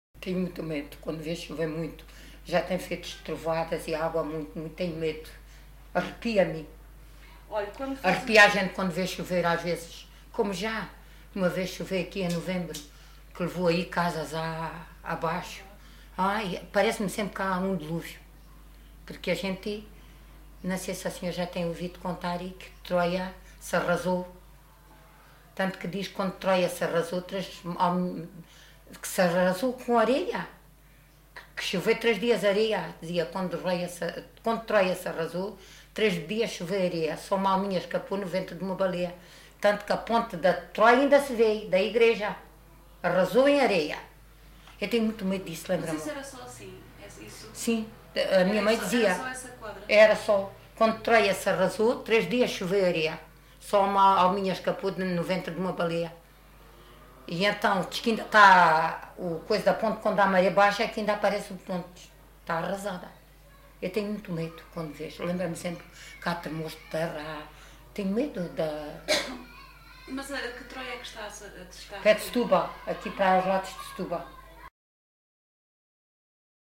LocalidadeAljustrel (Aljustrel, Beja)